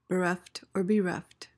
PRONUNCIATION: (bi-REFT) MEANING: adjective: Deprived of or lacking.